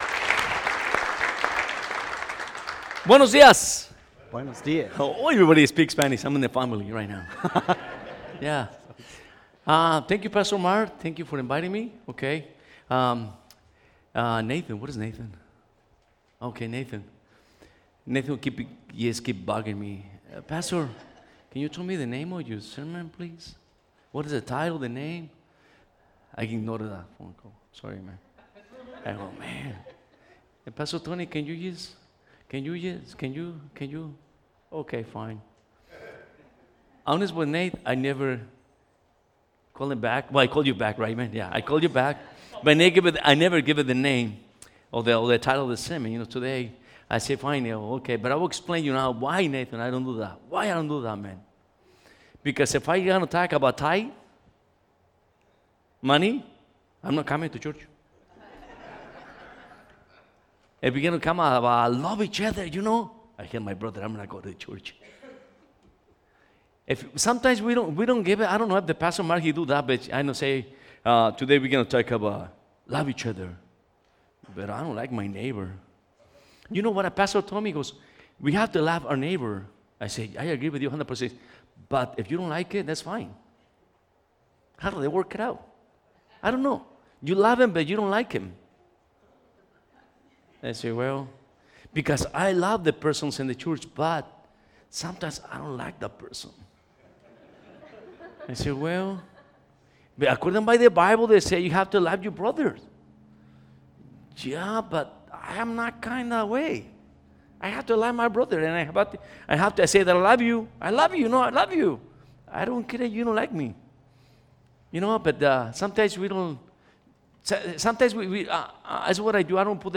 Combined Service